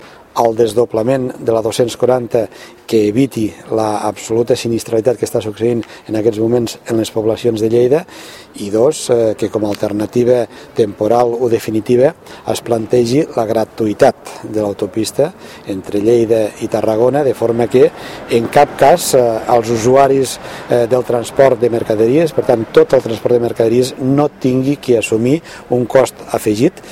Reñé ha fet aquestes declaracions als mitjans de comunicació durant la visita que ha fet aquest dijous a la comarca de les Garrigues.